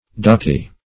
ducky \ducky\ (d[u^]k"[y^]), a.